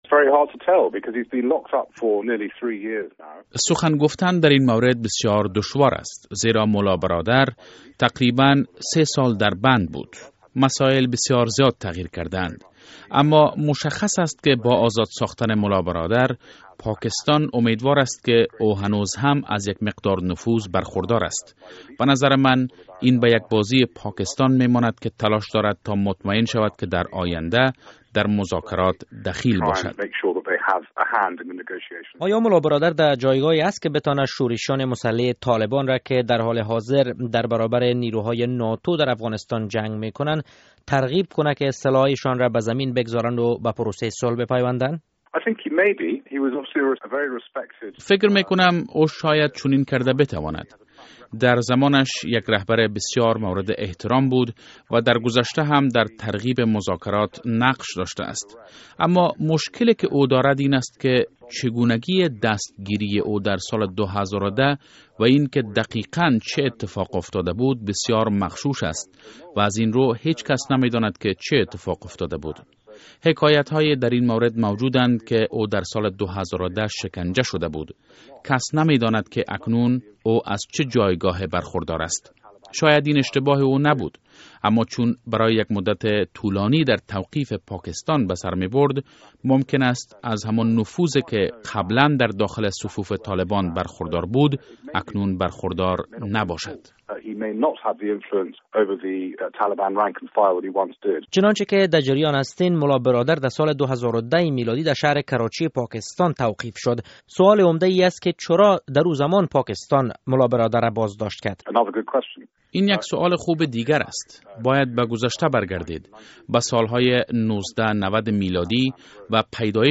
مصاحبه: ملا برادر برای طالبان از چی اهمیت برخوردار است؟